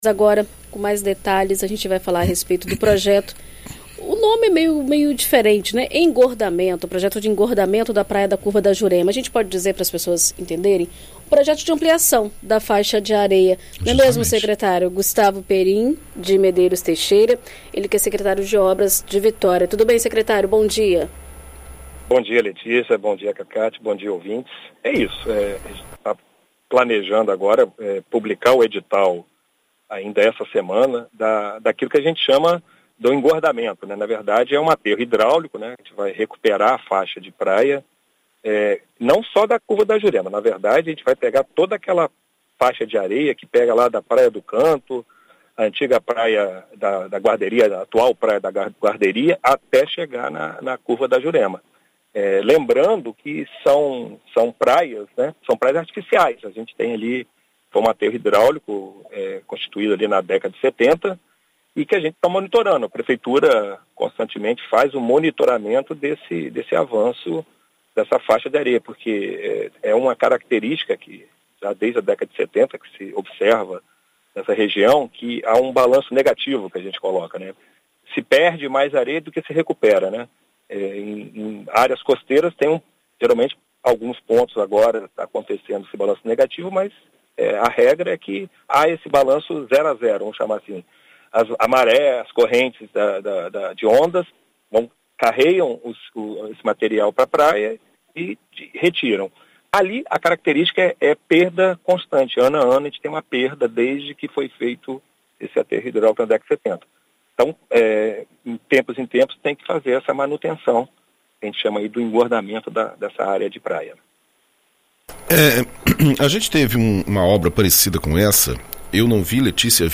Em entrevista à Rádio BandNews, o secretário de Obras de Vitória, Gustavo Perin, detalhou como será a obra de engordamento que será feita na orla da Praia do Canto e também na Curva da Jurema.